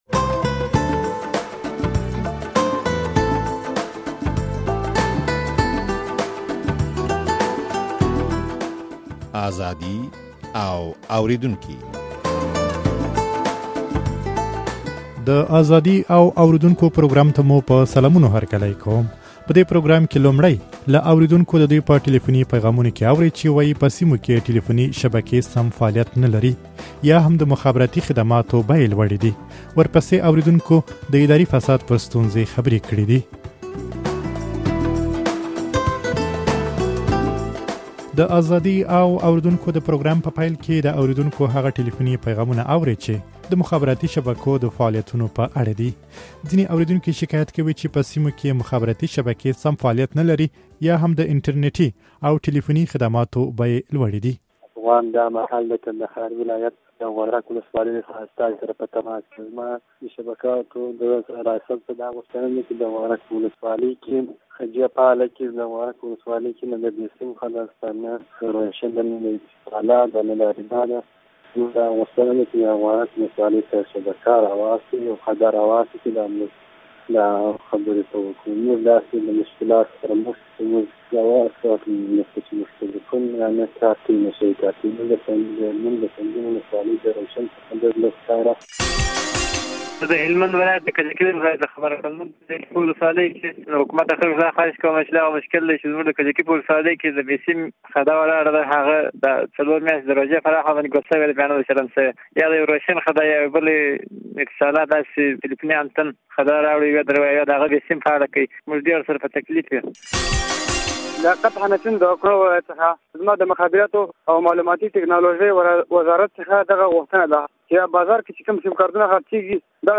په دې پروګرام کې لومړى له اورېدونکو د دوى په ټليفوني پيغامونو کې اورئ چې وايي په سيمو کې يې ټليفوني شبکې سم فعاليت نه لري،